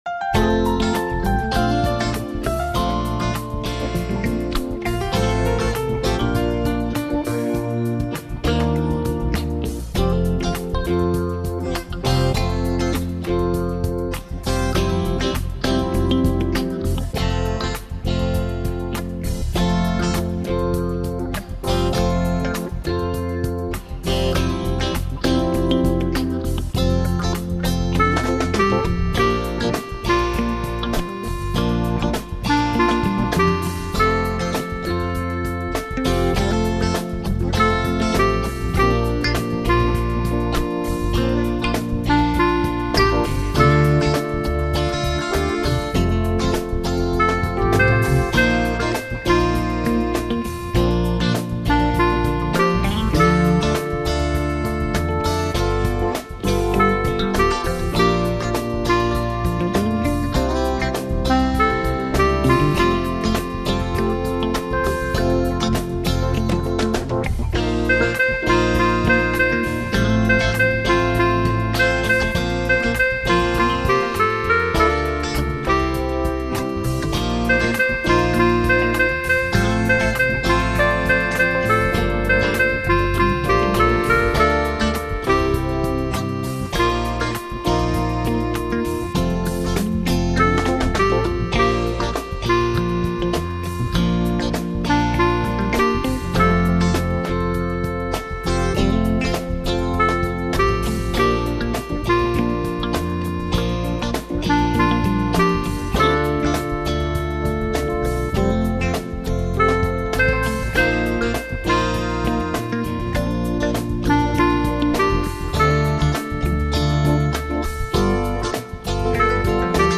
My backing shows off BIAB very well: